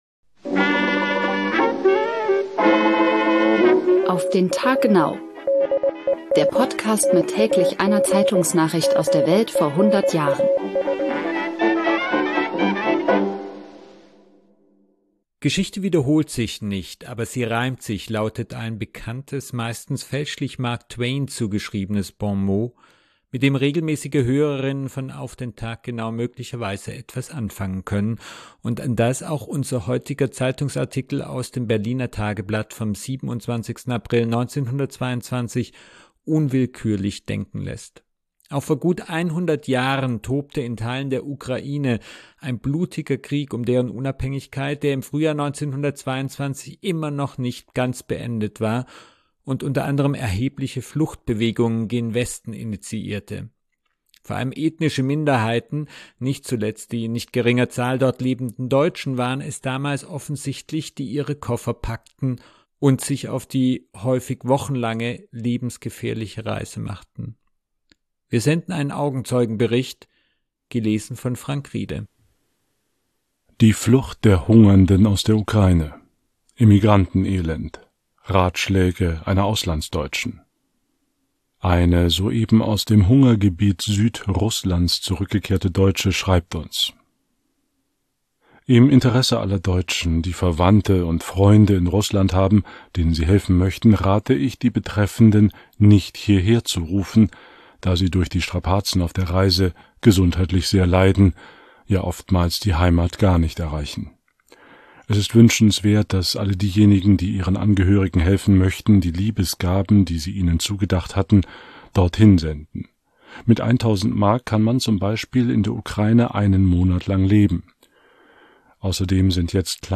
Wir senden einen Augenzeugenbericht, gelesen von